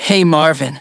synthetic-wakewords
ovos-tts-plugin-deepponies_Adachi Tohru_en.wav